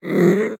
Minecraft Version Minecraft Version snapshot Latest Release | Latest Snapshot snapshot / assets / minecraft / sounds / mob / wolf / cute / growl3.ogg Compare With Compare With Latest Release | Latest Snapshot
growl3.ogg